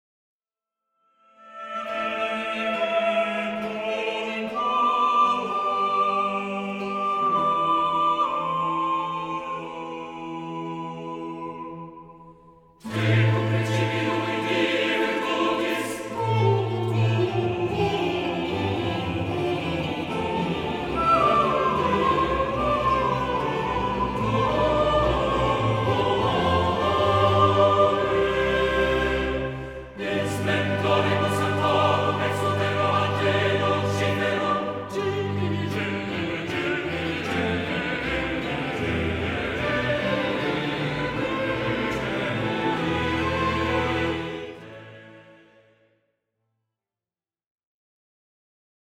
Un manifeste flamboyant de la révolution baroque